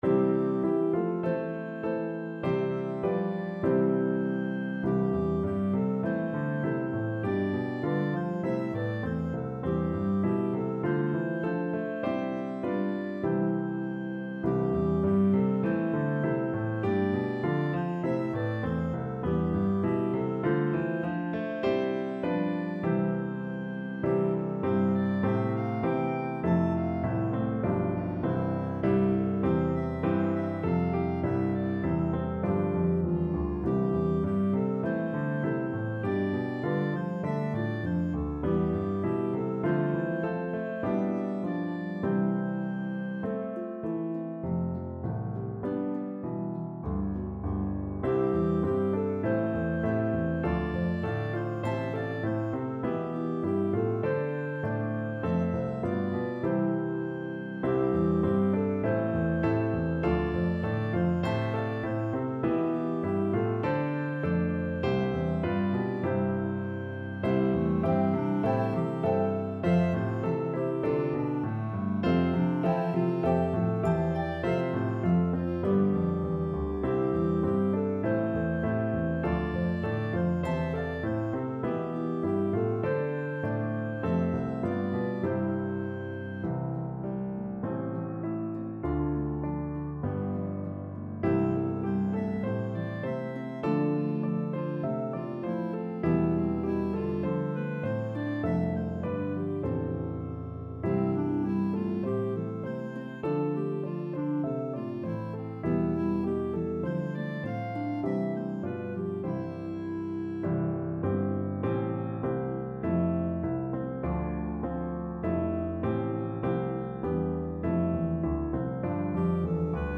a pentatonic melody